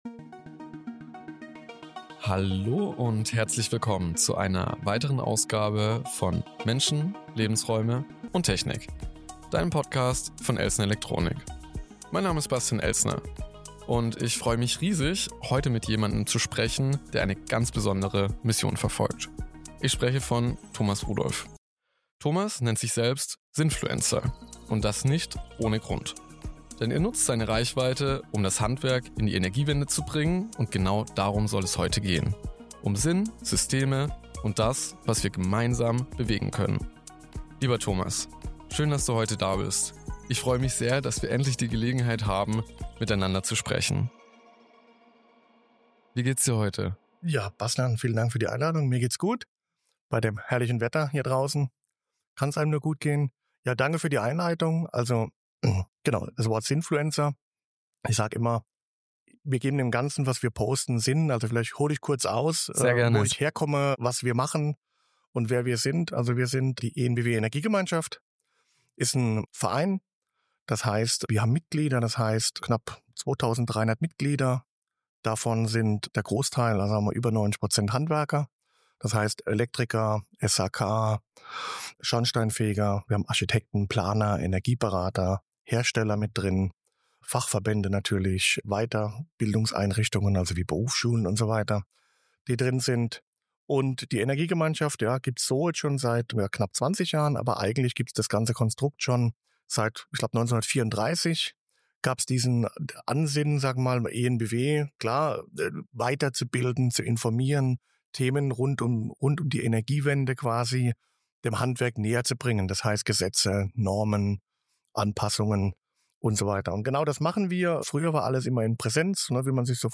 Ein Gespräch über Sinn, Systeme und smarte Gebäude!